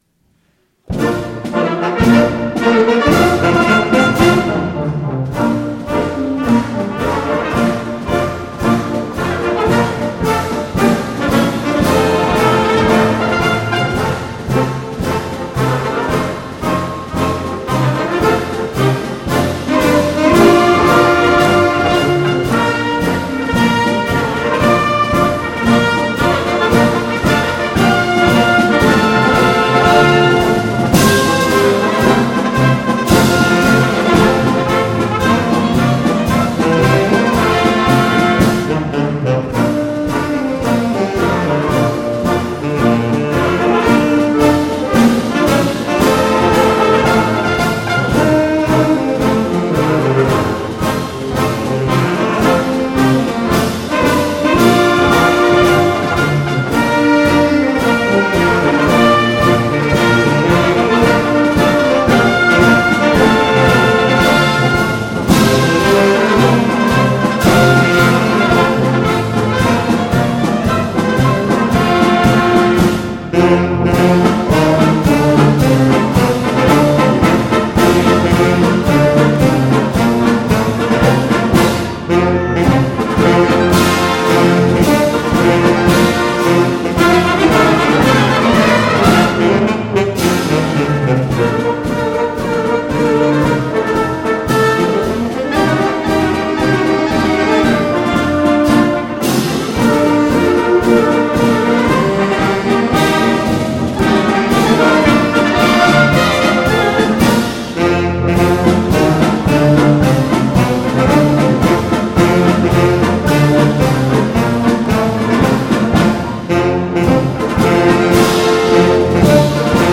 Konzert 2012